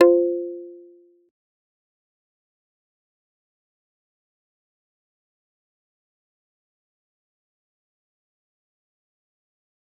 G_Kalimba-F4-pp.wav